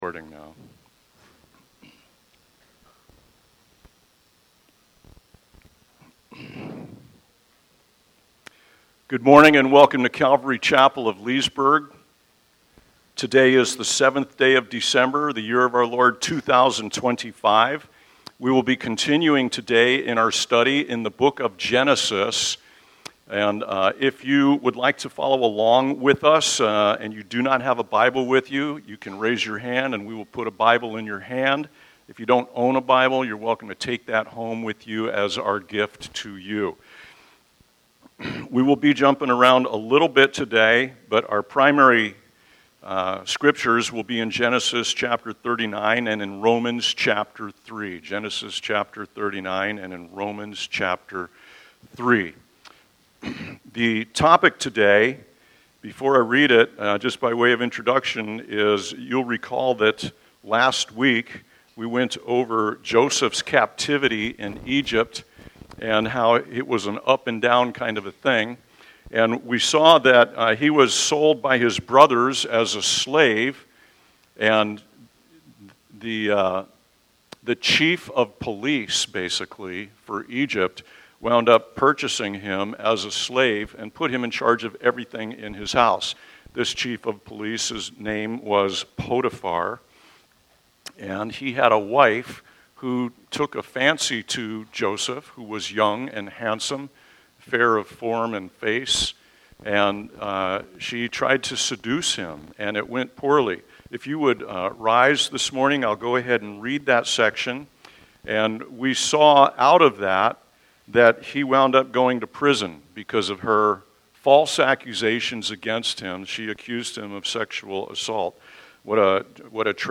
by Calvary Chapel Leesburg | Dec 7, 2025 | Sermons